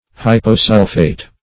Hyposulphate \Hy`po*sul"phate\, n.